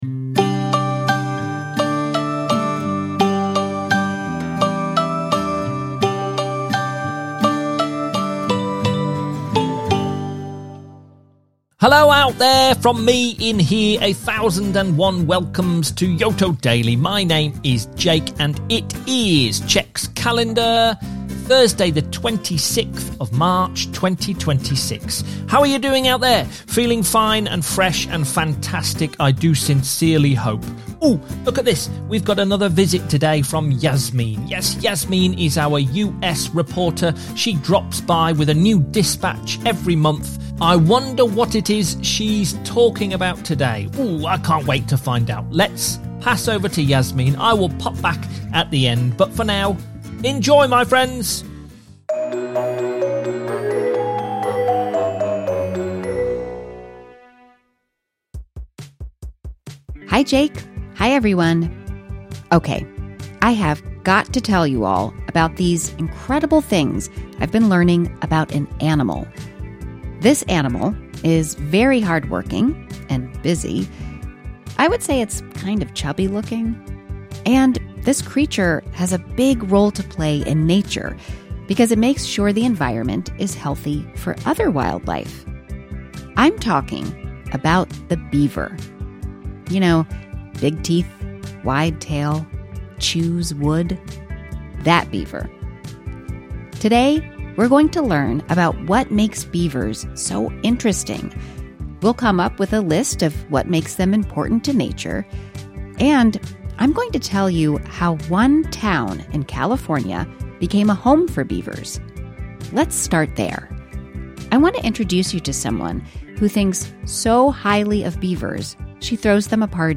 I don’t know how you feel about children’s content but screen-free Yoto is considered some of the best.